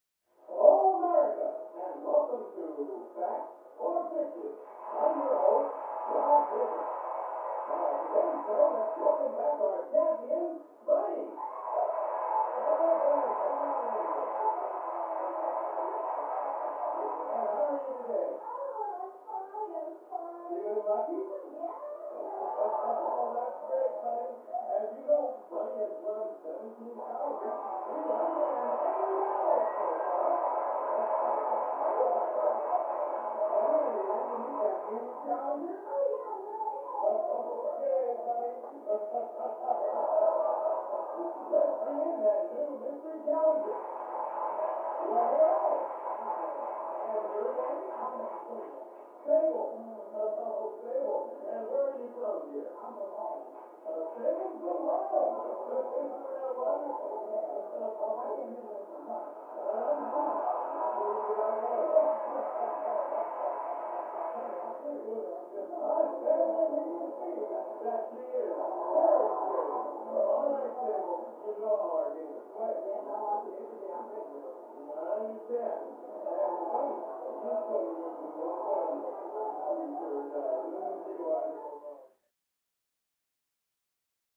Television; Full Game Show With Announcer, Crowd And Players. From Down Hallway.